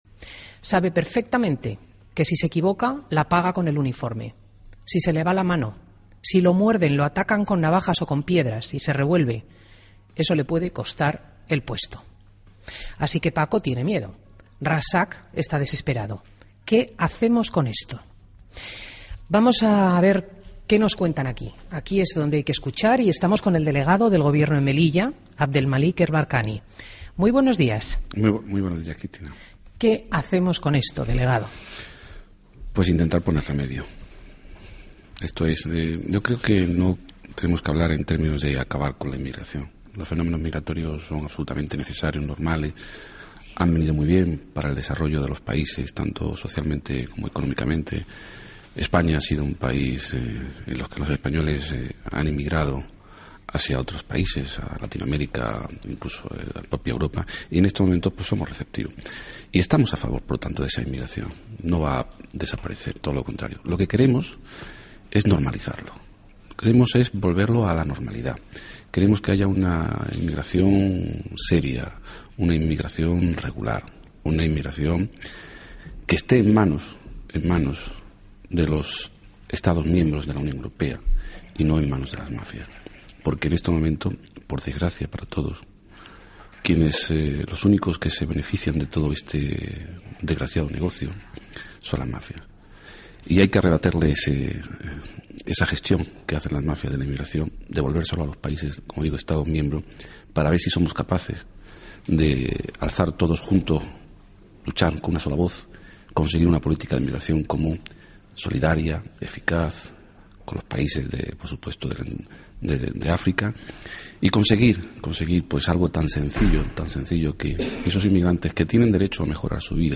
Entrevista a Abedlmalik El Barkani en Fin de Semana COPE